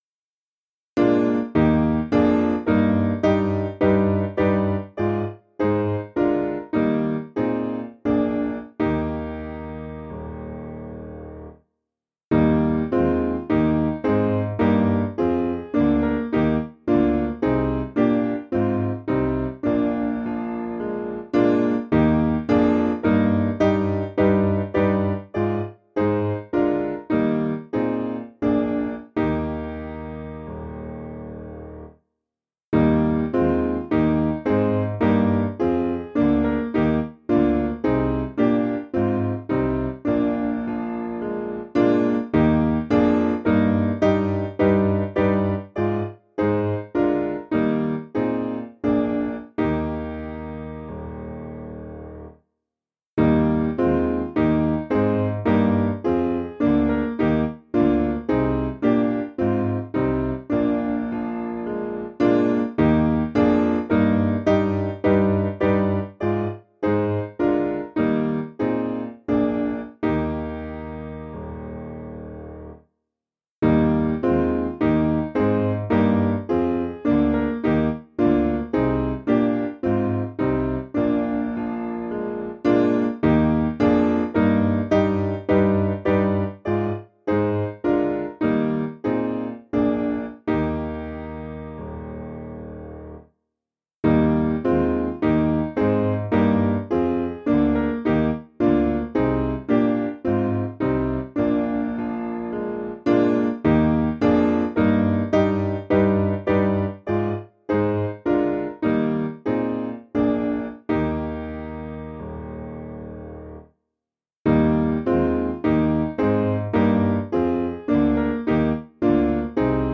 Meter: 8.6.8.6
Key: E♭ Major